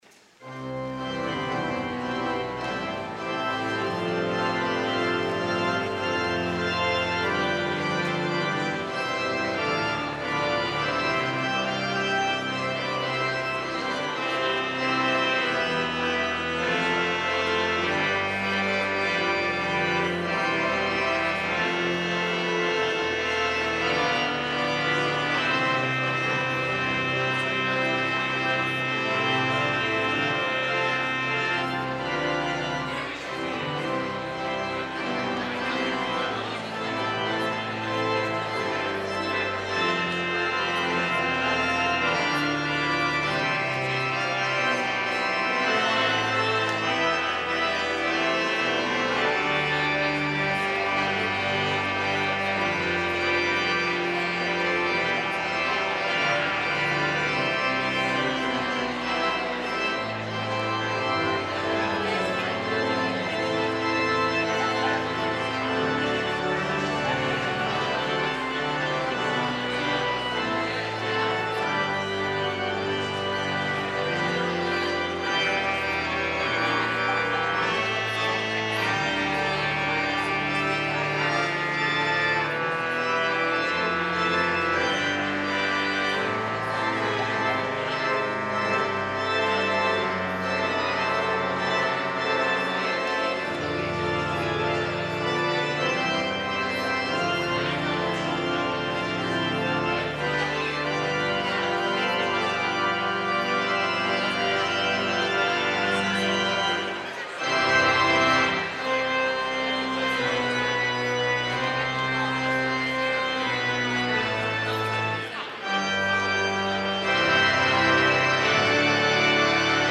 organ
guest organist